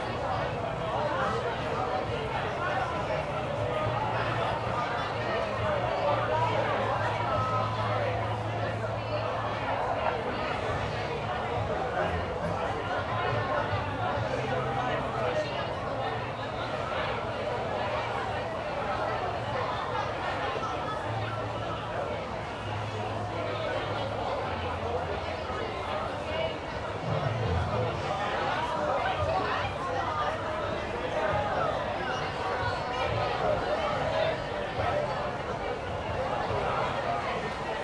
background_crowd_people_chatter_loop_01.wav